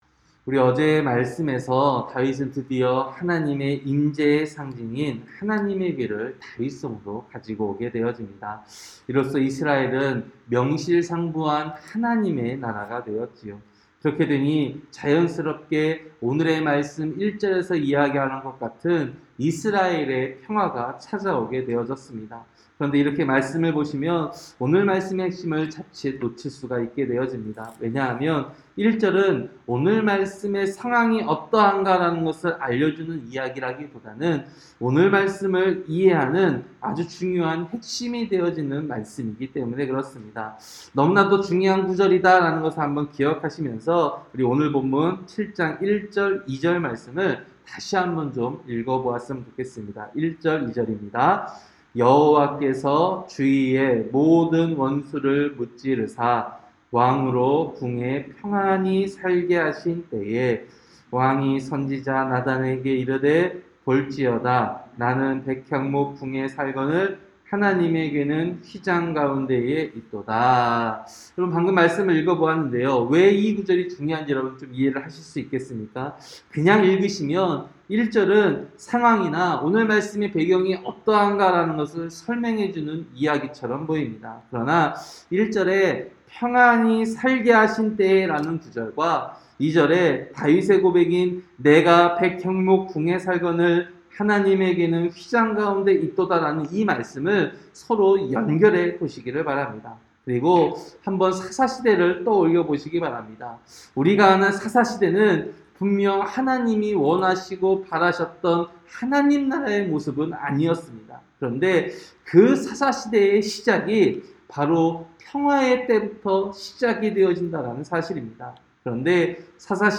새벽설교-사무엘하 7장